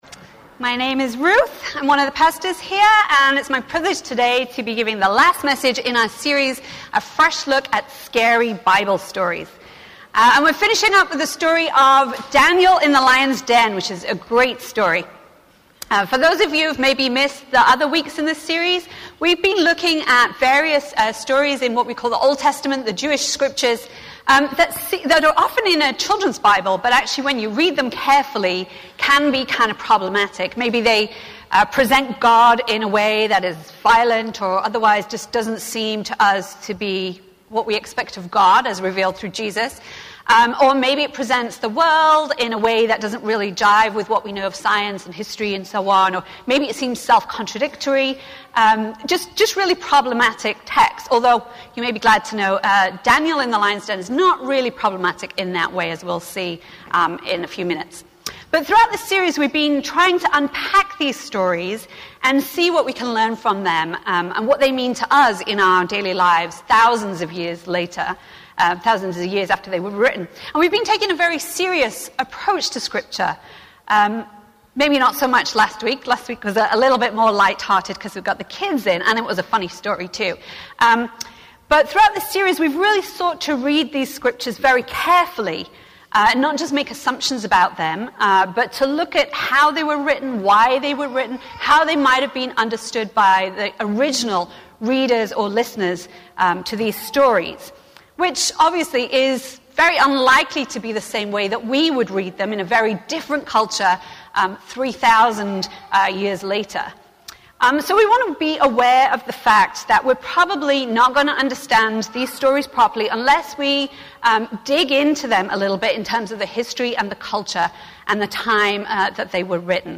A message from the series "Stories of Faith." In this last week of the series, we explored the story in Luke's Gospel of the woman who washed Jesus' feet.